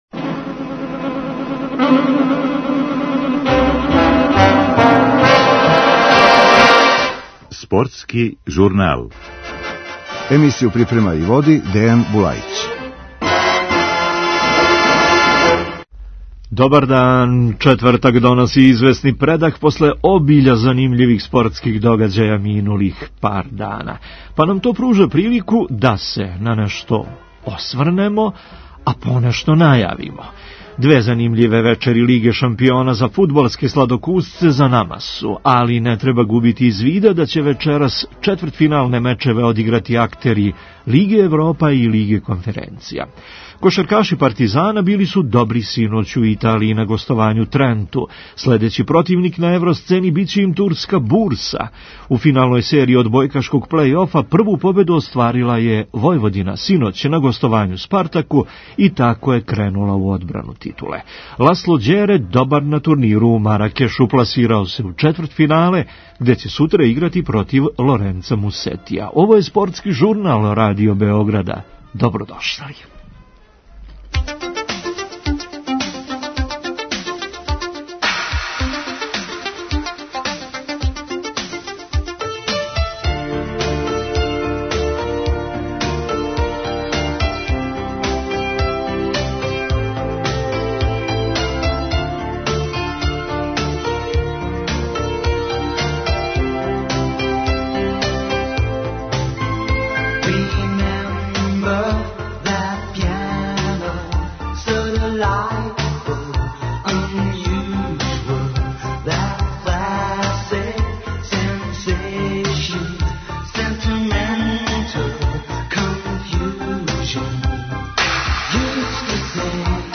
Čućemo utiske i legendarnog fudbalera Dušana Savića o prenosima na radiju.